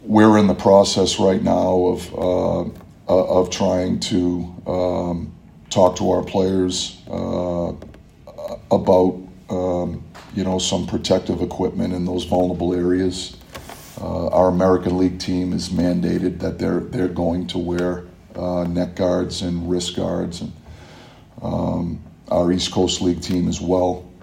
Coach Mike Sullivan commented on the matter following yesterday’s practice.